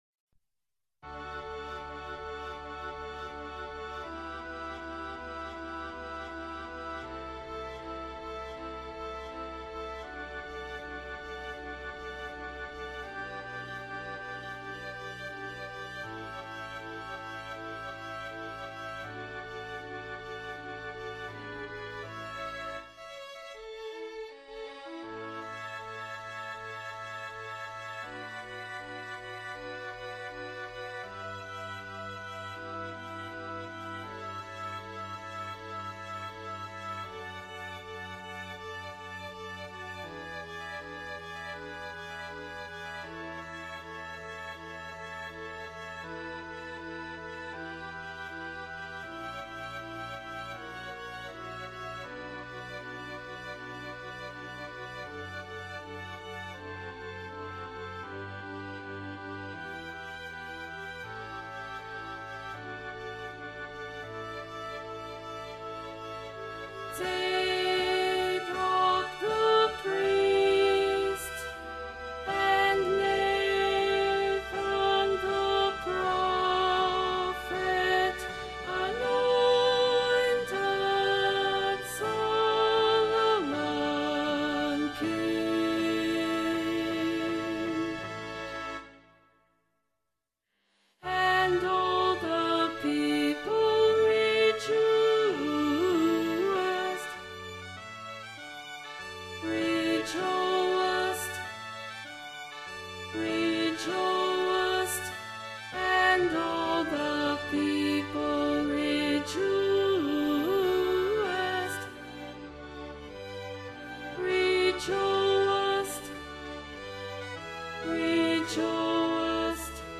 Emphasised voice and other voices